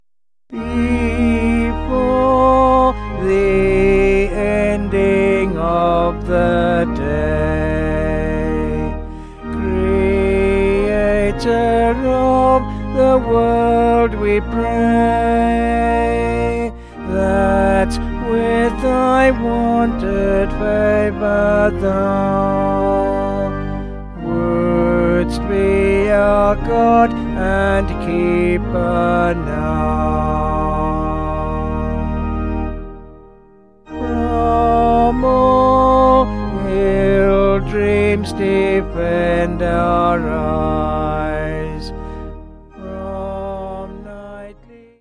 (BH)   3/Eb
Vocals and Organ